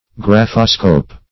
Search Result for " graphoscope" : The Collaborative International Dictionary of English v.0.48: Graphoscope \Graph"o*scope\, n. [Gr. gra`fein to write + -scope.] 1.
graphoscope.mp3